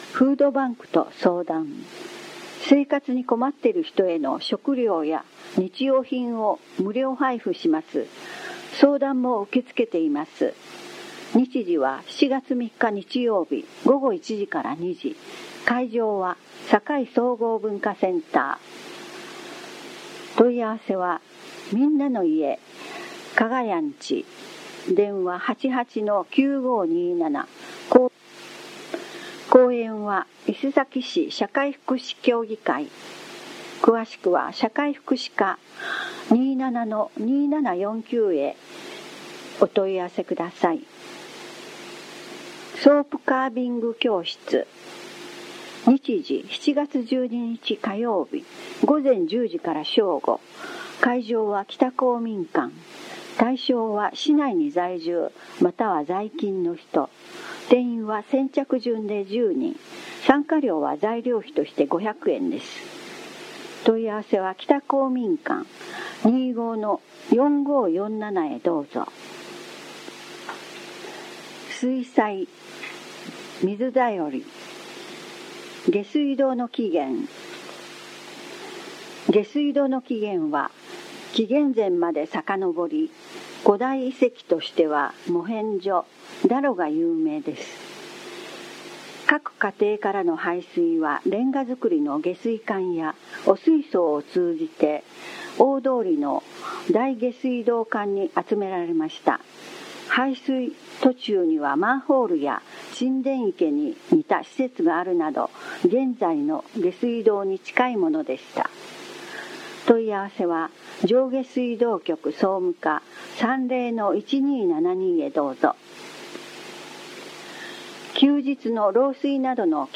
声の広報は目の不自由な人などのために、「広報いせさき」を読み上げたものです。
朗読